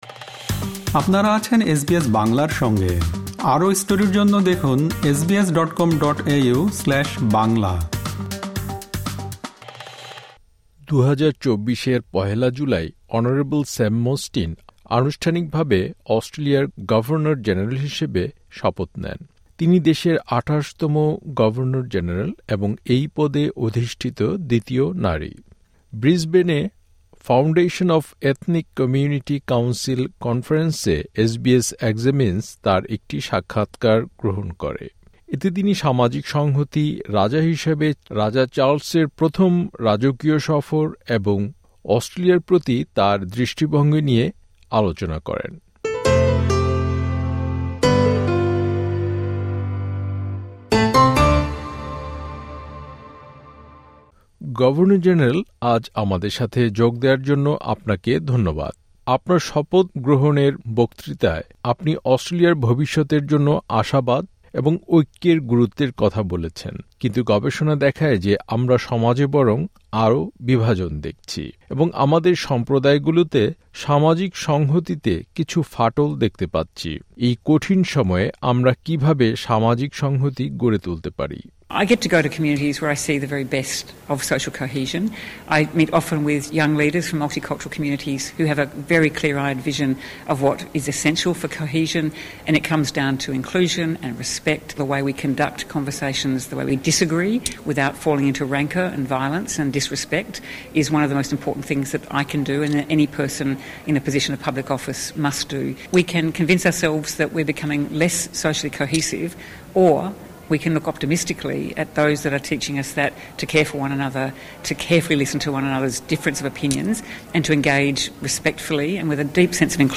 SBS Examines: অস্ট্রেলিয়ার গভর্নর জেনারেলের সাক্ষাৎকার
ব্রিসবেনে ফাউন্ডেশন অফ এথনিক কমিউনিটি কাউন্সিল কনফারেন্সে এসবিএস এক্সামিনস গভর্নর-জেনারেলের একটি সাক্ষাৎকার গ্রহণ করে। এতে তিনি সামাজিক সংহতি, রাজা হিসেবে রাজা চার্লসের প্রথম রাজকীয় সফর এবং অস্ট্রেলিয়ার প্রতি তার দৃষ্টিভঙ্গি নিয়ে আলোচনা করেন।